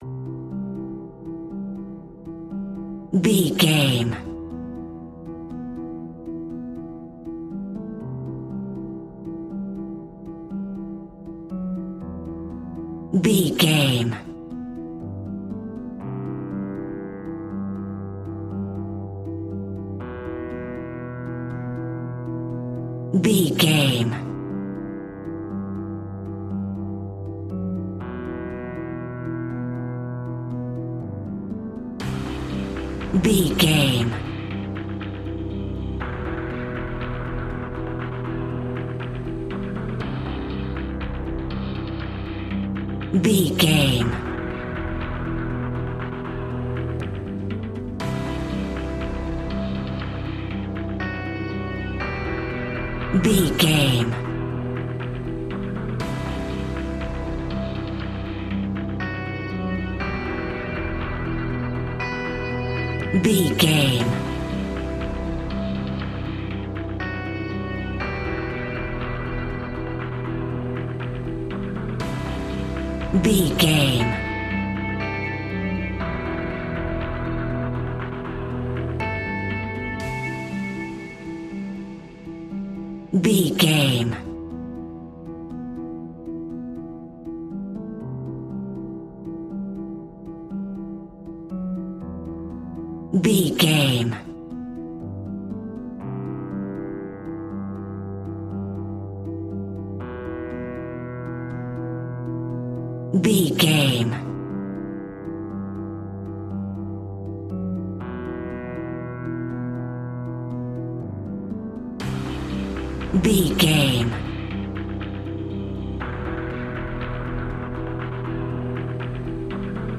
Aeolian/Minor
dramatic
intense
piano
electric guitar
synthesiser
drums
percussion
suspenseful
creepy
horror music